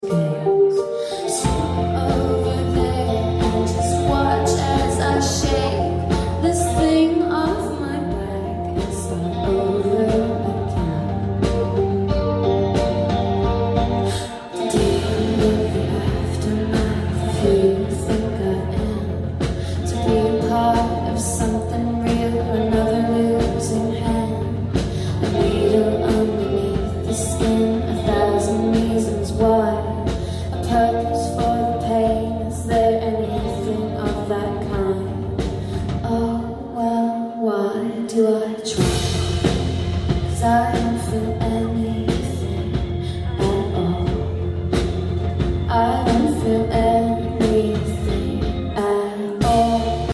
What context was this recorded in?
live at Birmingham O2 Academy3